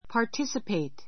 participate pɑː r tísəpeit パー ティ スィペイ ト 動詞 participate in ～ で ～に参加する, ～に加わる （take part in ～） Students are expected to participate in club activities.